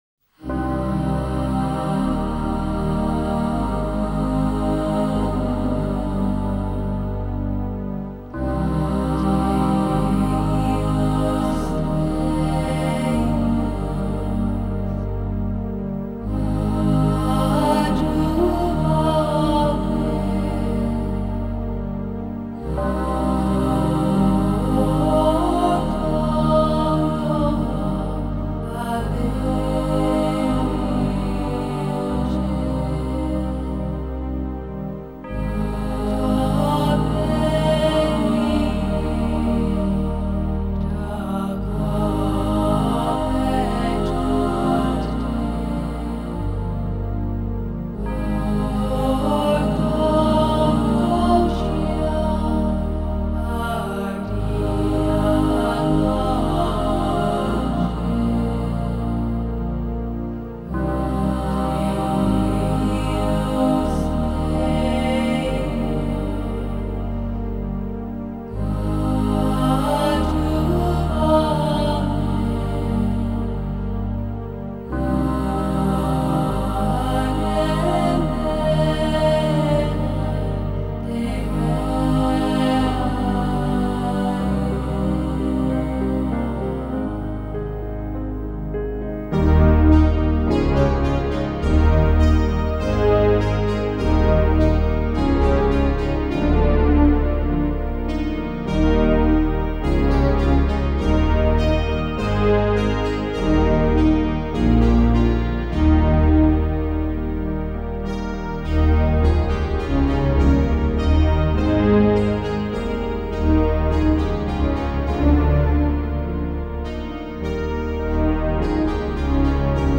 장르: Electronic
스타일: Modern Classical, Minimal, Ambient